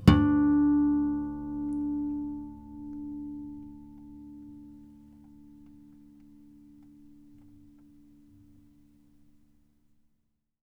harmonic-05.wav